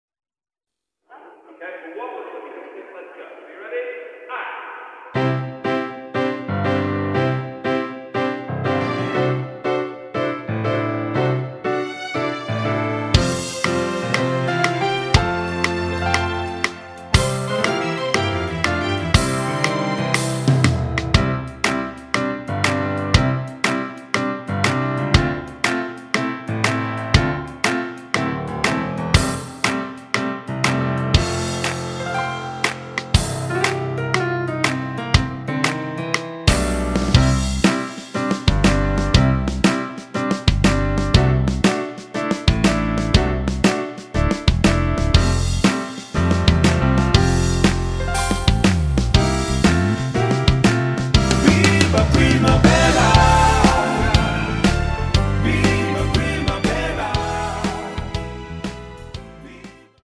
키 C 가수